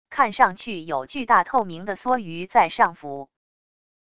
看上去有巨大透明的梭鱼在上浮wav音频生成系统WAV Audio Player